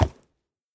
sounds / mob / horse / wood1.mp3
wood1.mp3